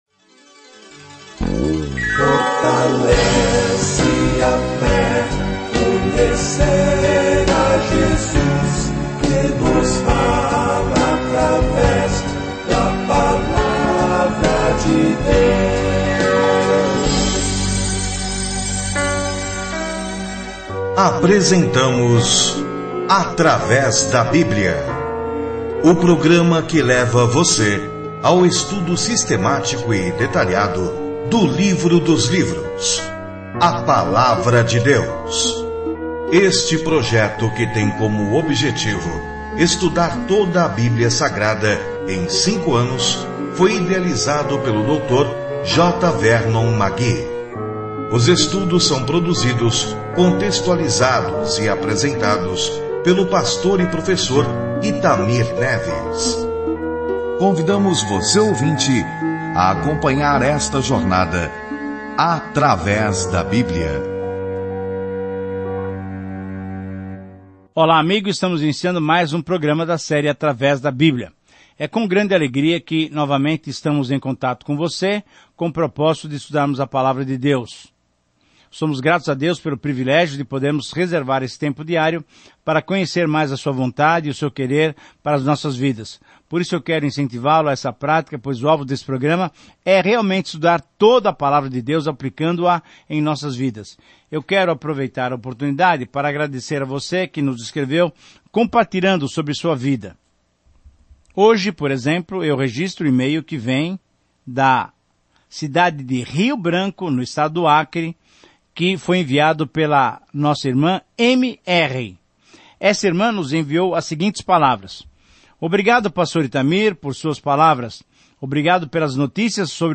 As Escrituras Ester 2:1-23 Dia 2 Começar esse Plano Dia 4 Sobre este Plano Deus sempre cuidou do seu povo, mesmo quando conspirações genocidas ameaçam a sua extinção; uma história incrível de como uma garota judia enfrenta a pessoa mais poderosa do mundo para pedir ajuda. Viaje diariamente por Ester enquanto ouve o estudo em áudio e lê versículos selecionados da palavra de Deus.